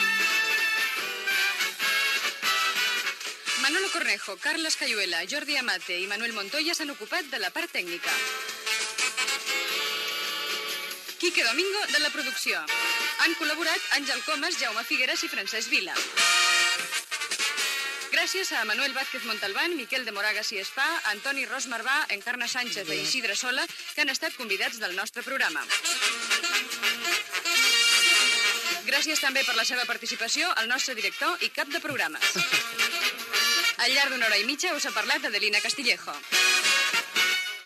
Hora, sintonia i inici del programa amb motiu del 60 aniversari de Ràdio Barcelona, esment de les bondats i utilitats de la ràdio.
Entreteniment